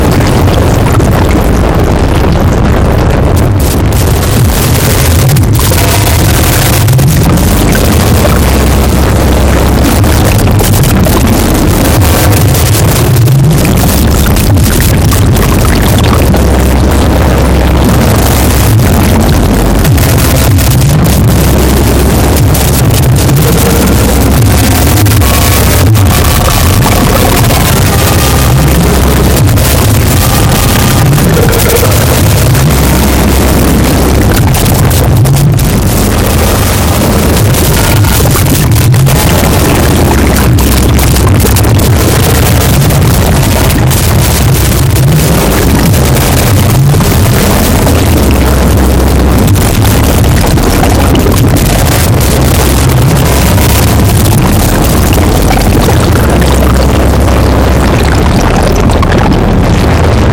Find me another drum machine that can go from this
Is it only one hi hat with delay, analog? Ducking kick via fx track?